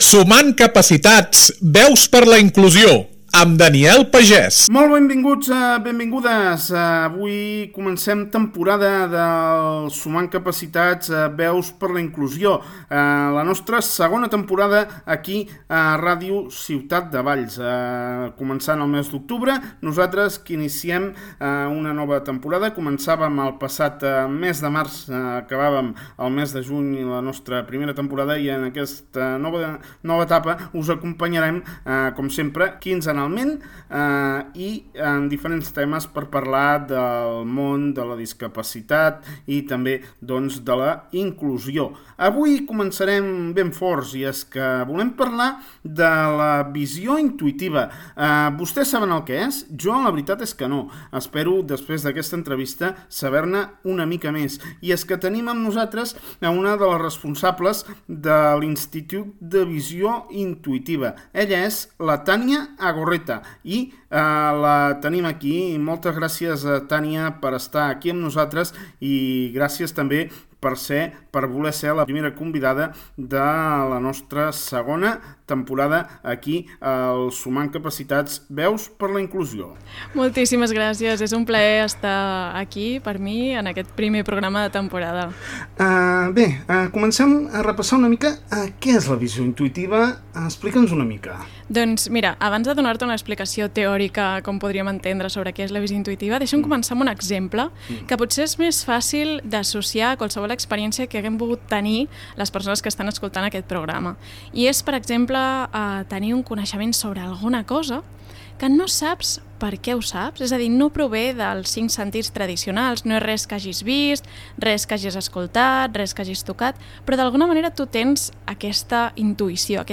Un espai d’entrevistes sobre el món de la discapacitat, la inclusió i el Tercer Sector.